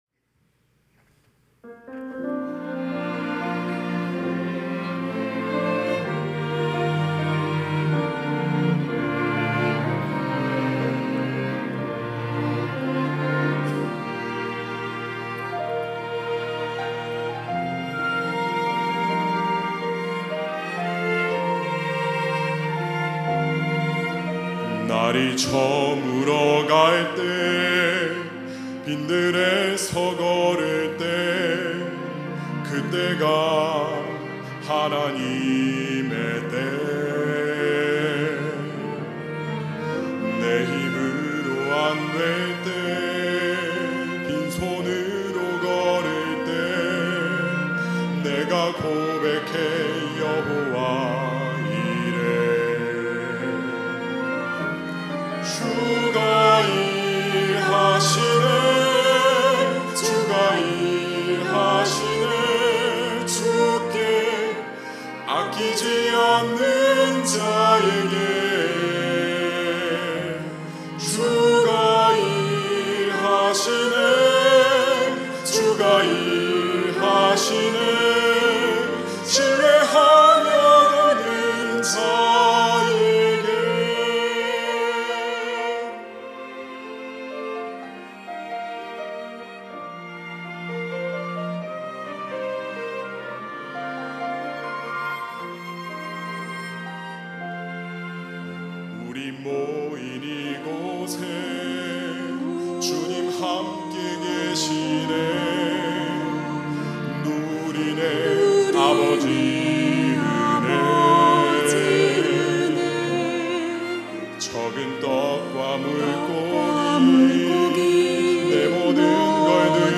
특송과 특주 - 주가 일하시네